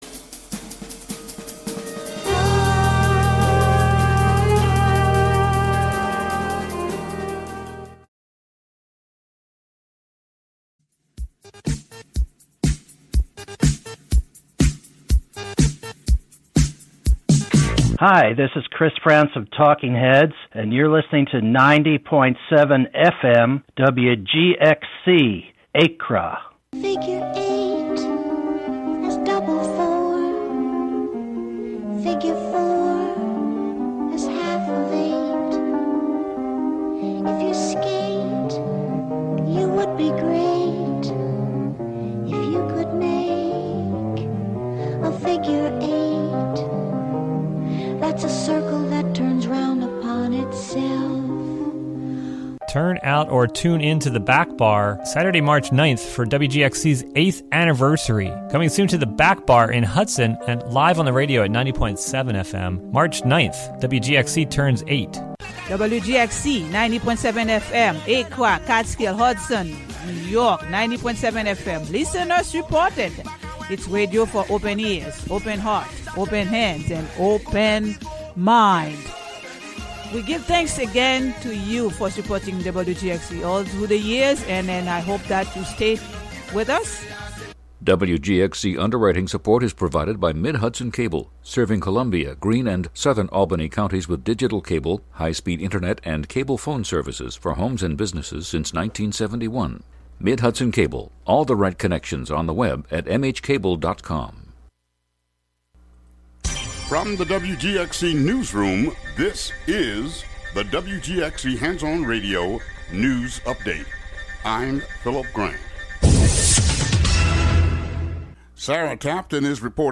3pm Broadcasting electronic music without borders.